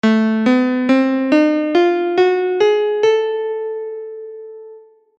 The 5|1 mode of the Kleismic[7] in 15edo.